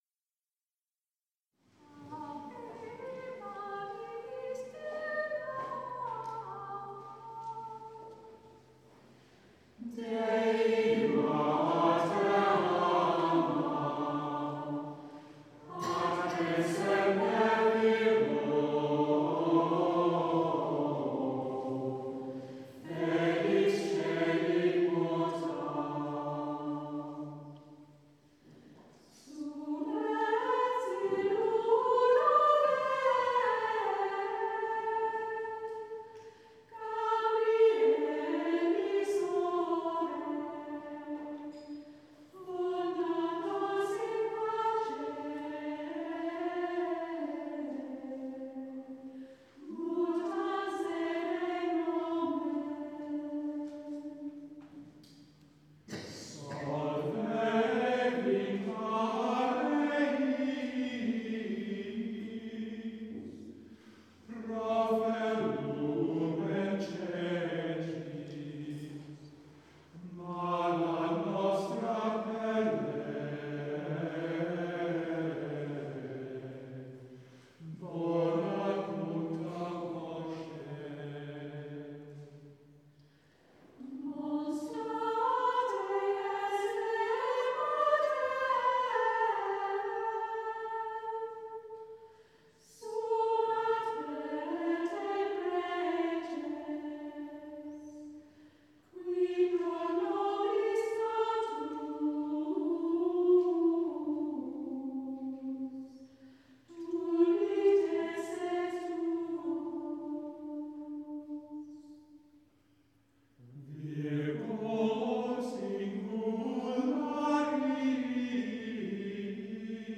The Choir of Boxgrove Priory
with The Boxgrove Consort of Viols
Recorded live in Boxgrove Priory on the evening of 25th June 2013
Ave maris stella - plainsong